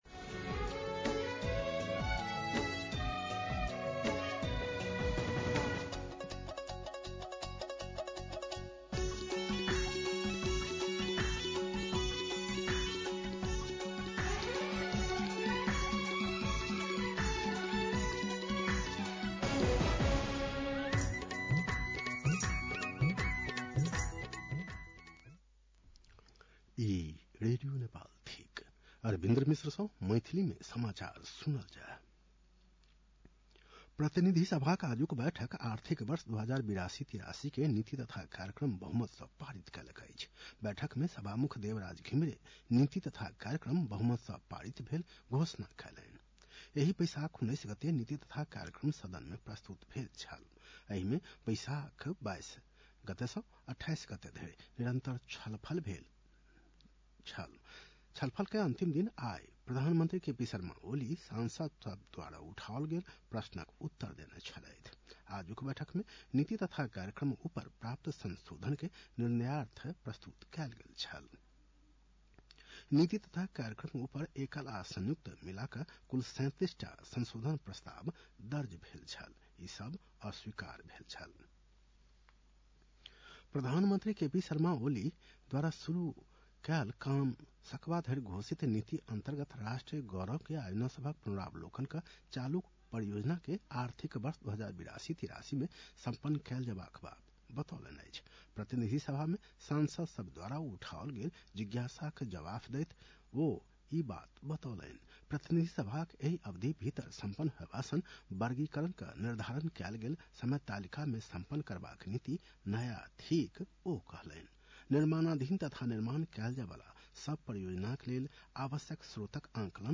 मैथिली भाषामा समाचार : २८ वैशाख , २०८२
6.-pm-maithali-news-.mp3